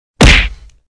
جلوه های صوتی
دانلود صدای مشت زدن 2 از ساعد نیوز با لینک مستقیم و کیفیت بالا